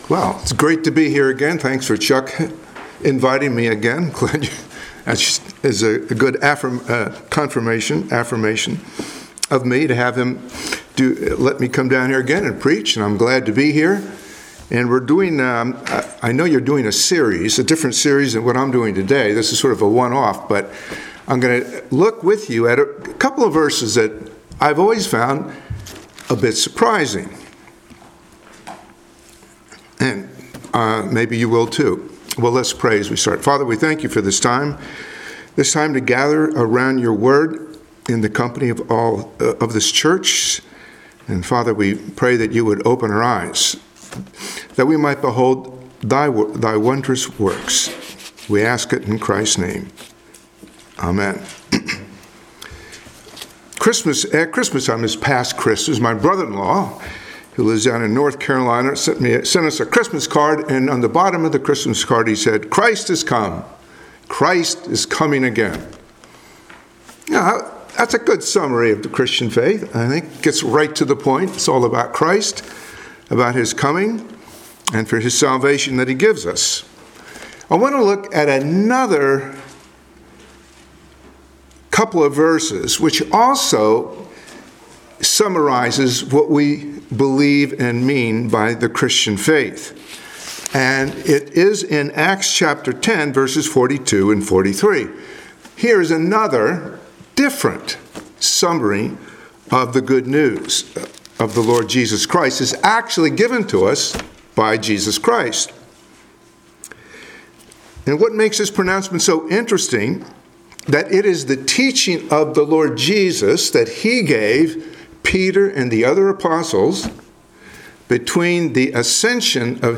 Passage: Acts 10:42-43 Service Type: Sunday Morning Worship